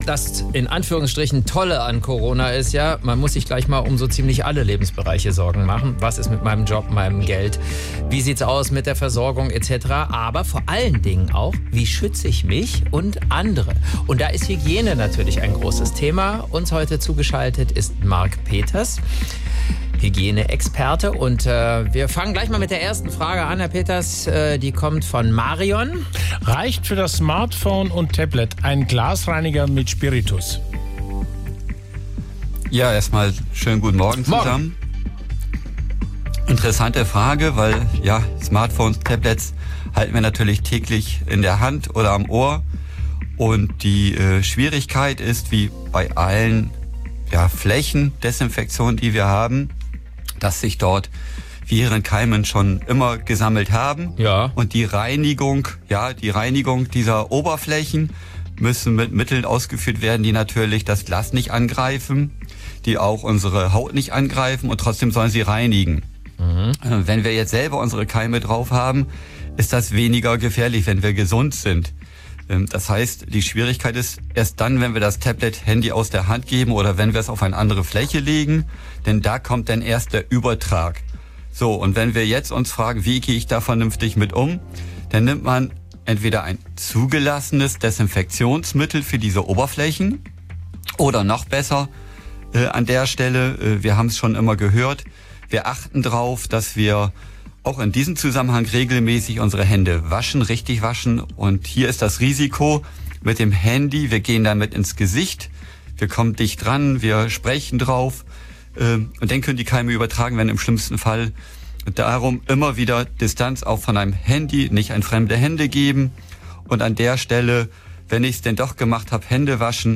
SWR3-Audio: Beitrag anhören